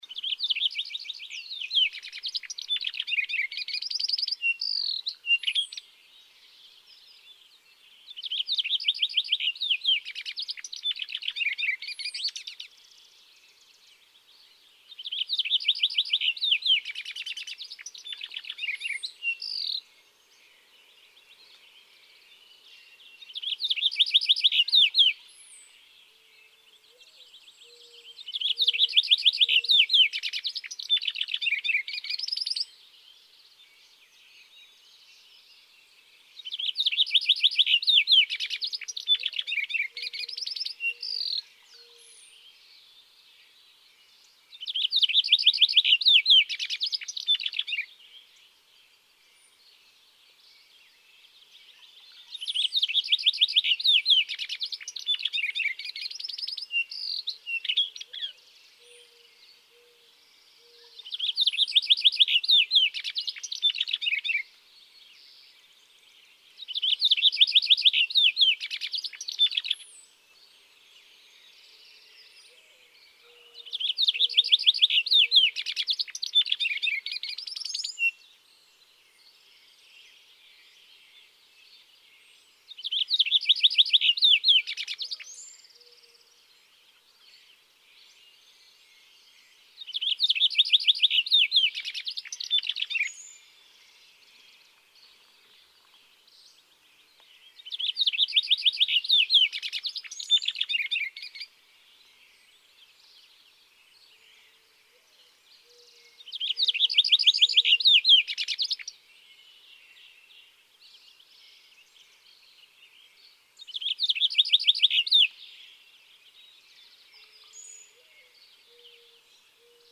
Cassin’s finch
♫94. Normal song, rather similar to that of the closely related house finch. Hear the imitated call note of the western tanager at 0:05.4?
Lava Hot Springs, Idaho.
094_Cassin's_Finch.mp3